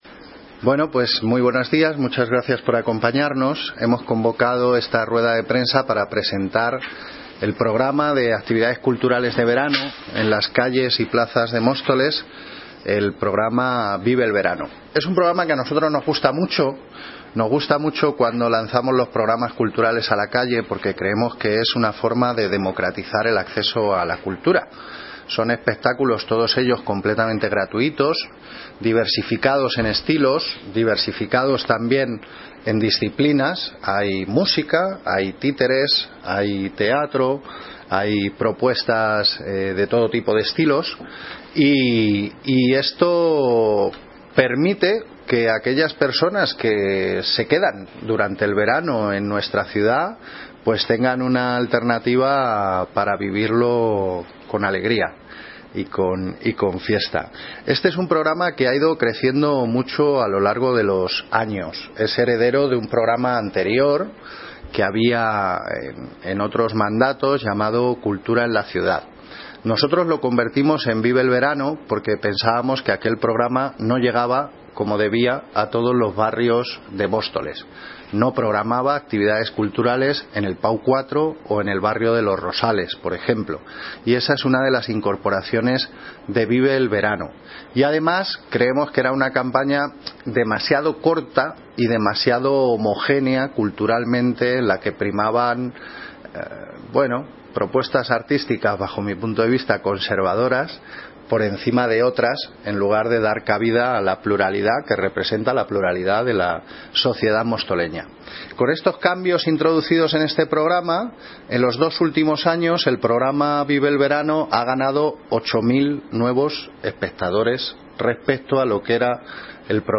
Audio - Gabriel Ortega (Concejalía de Cultura y Políticas Medio Ambientales) Sobre Vive el Verano